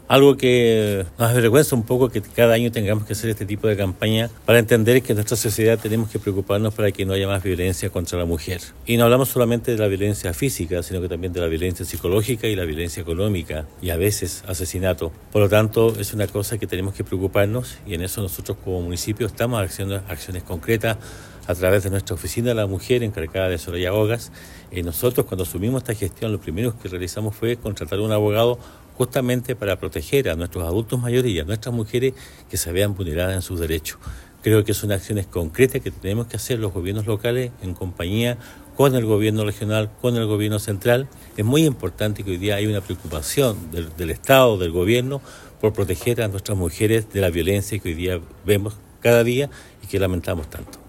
La actividad se realizó en la Sala de Sesiones del Municipio y contó con la participación de autoridades regionales, provinciales y locales; además de dirigentas sociales.
El Alcalde de Osorno, Emeterio Carrillo, enfatizó en que se debe tomar conciencia de lo grave que es la violencia de género, además explicó que desde el gobierno local se está buscando avanzar en cuanto a esta materia.